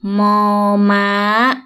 – moor / maa